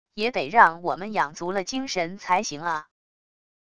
也得让我们养足了精神才行啊wav音频生成系统WAV Audio Player